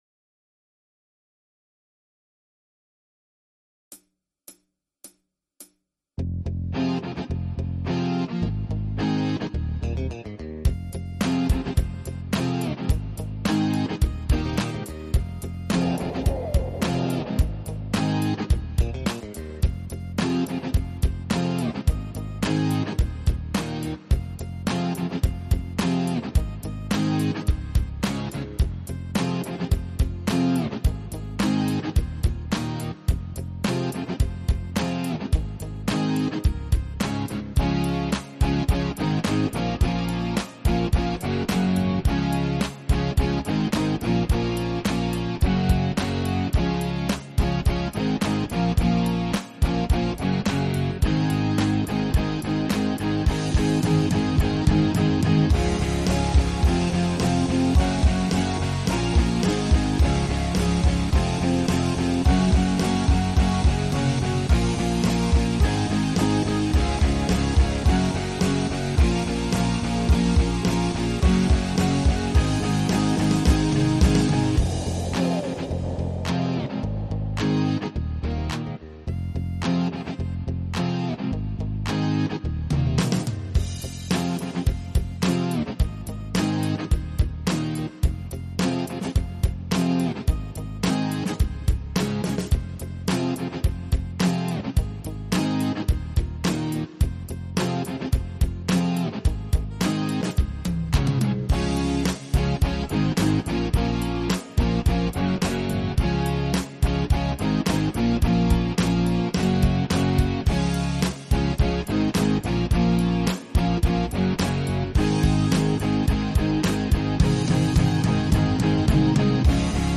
mehrspurige Instrumentalversion